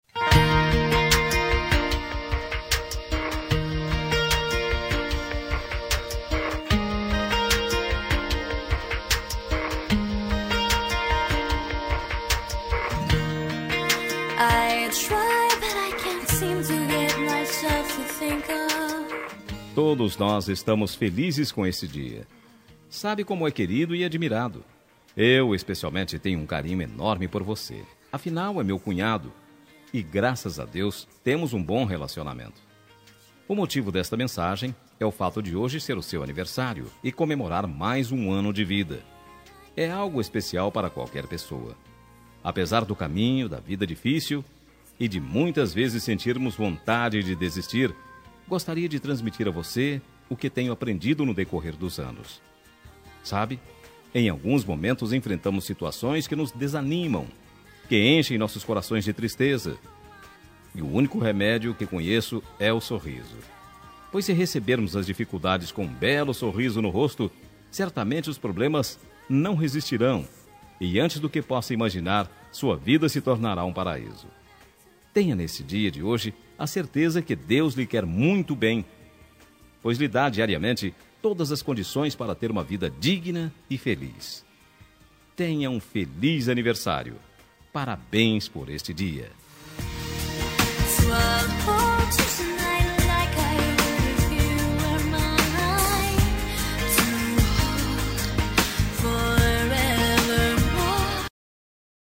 Aniversário de Cunhado – Voz Masculina – Cód: 5235